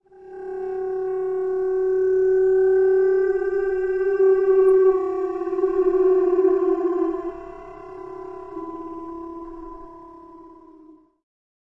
080809狗
描述：德纳利国家公园狗拉雪橇远距离演示。 狗狗们因为想拉雪橇而吠叫、呜咽、嚎叫。 录音接近尾声时的掌声。
标签： 阿拉斯加 吠叫 迪纳利 狗拉雪橇 嚎叫 狗窝 呜呜
声道立体声